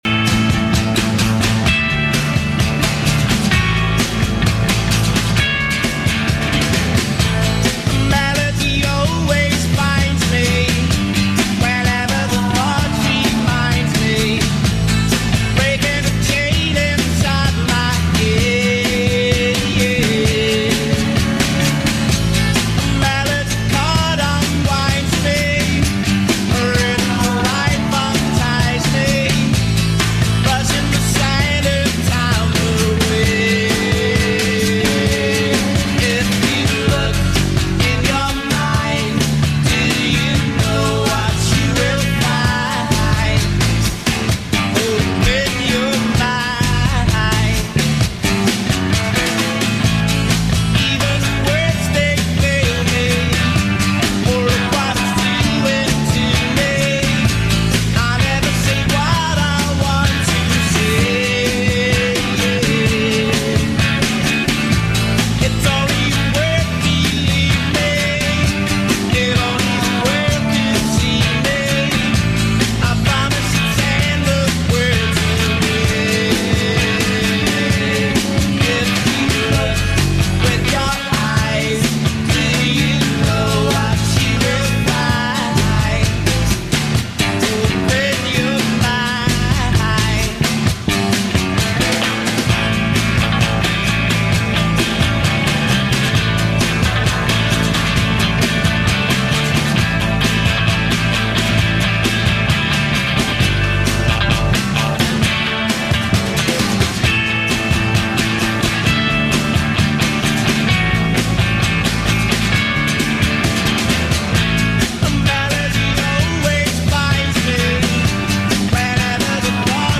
vocals, guitar
bass, backing vocals